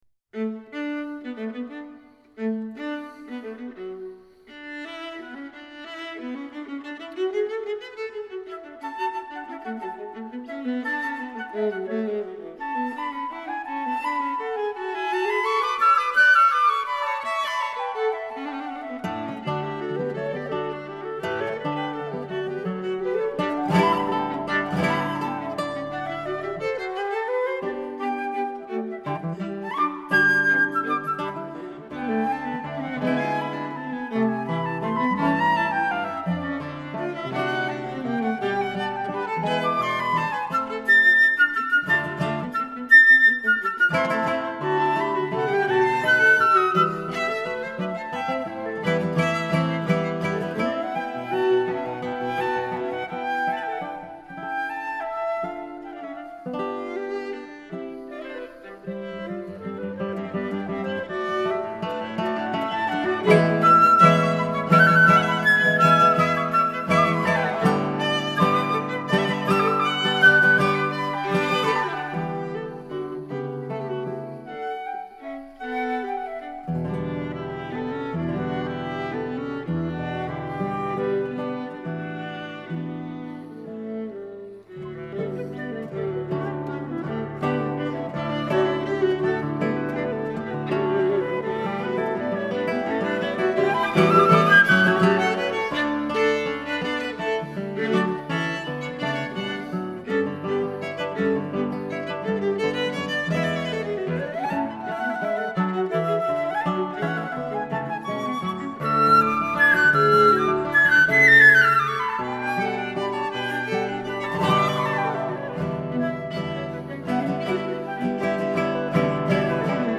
Instrumental Music
My variation (a fugue)
SCORING:  Flute, viola, guitar